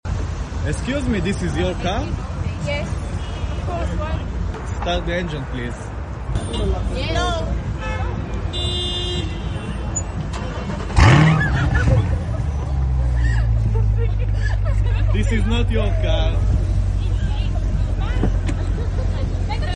If someone is touching or sitting on your car, start the engine remotely and this is what will happen 😉